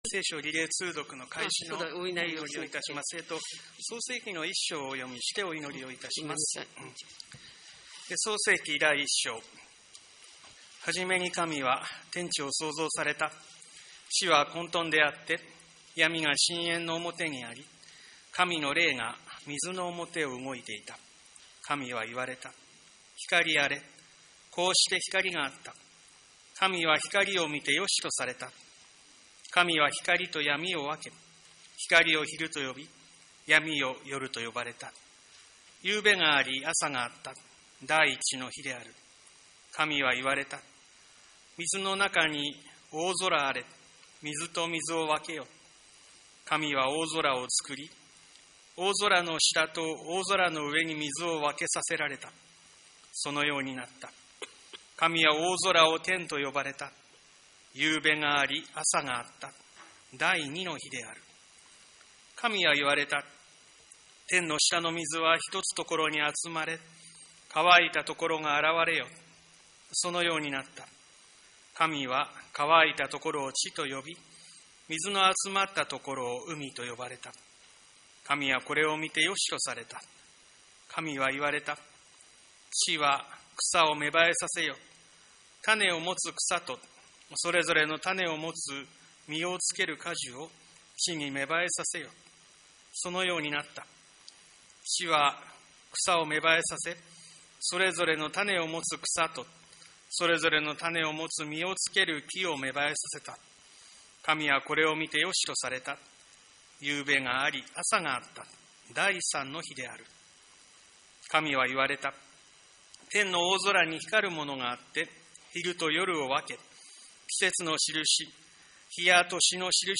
教会礼拝堂で、講壇用の大型聖書を用いて、リレー形式で聖書を声を出して読み進めます。
4月6日（日）礼拝後、聖書全巻リレー通読　開始の祈り
2025聖書全巻リレー通読開始の祈り音声　創世記　第1章の朗読と祈り